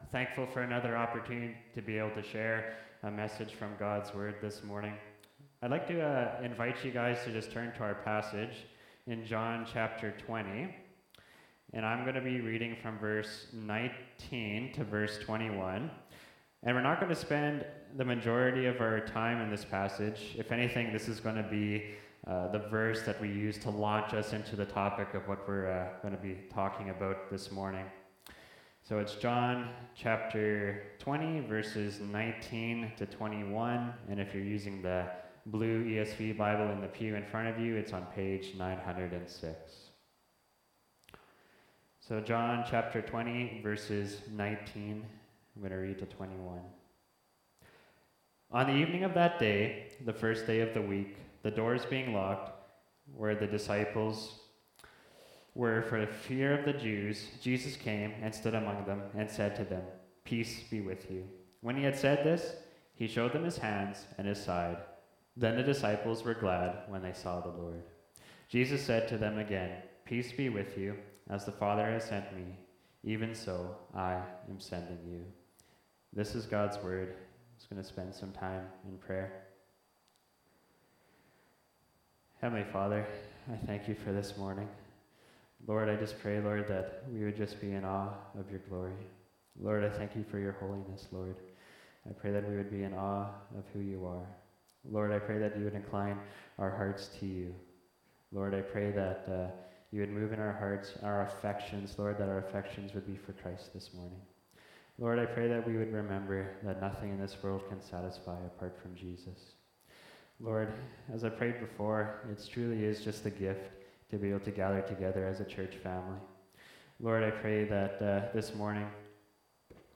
Nov 03, 2024 Courageous Evangelism (John 20:21) MP3 SUBSCRIBE on iTunes(Podcast) Notes Discussion Sermons in this Series This sermon was recorded in Salmon Arm and preached in both SA and Enderby.